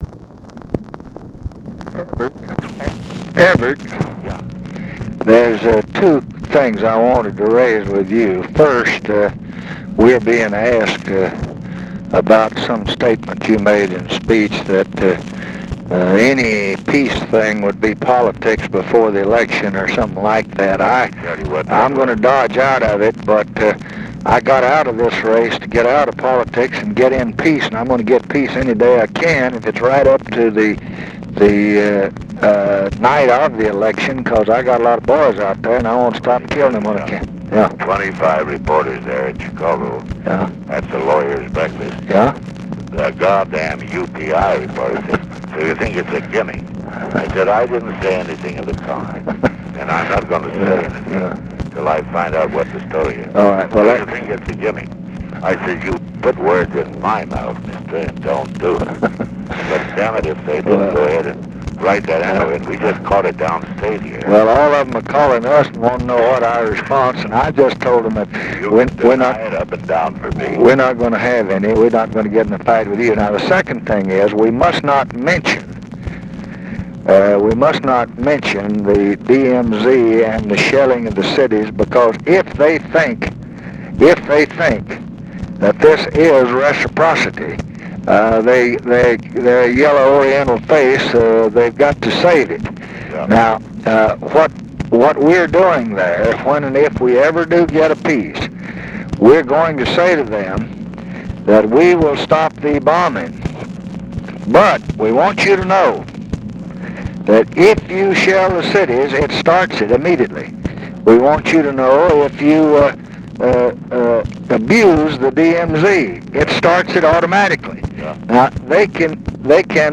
Conversation with EVERETT DIRKSEN, October 16, 1968
Secret White House Tapes